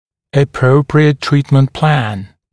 [ə’prəuprɪət ‘triːtmənt plæn][э’проуприэт ‘три:тмэнт плэн]правильно составленный план лечения